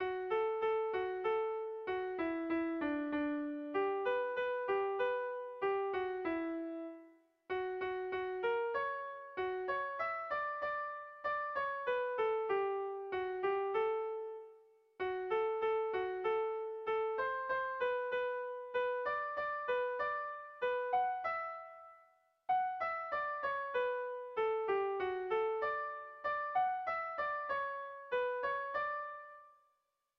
Zortziko handia (hg) / Lau puntuko handia (ip)
ABDE